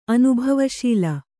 ♪ anubhavaśila